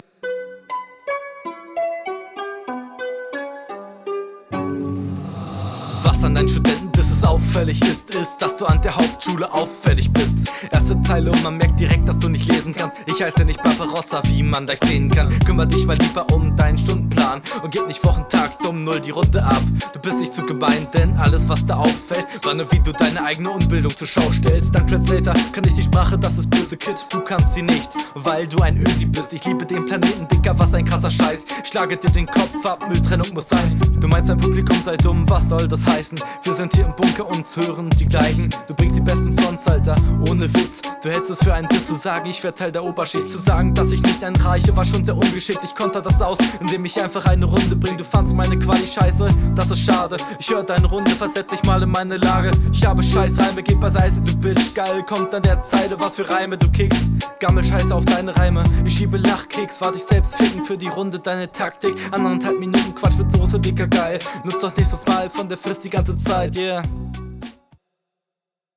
Flow bisschen unsauber.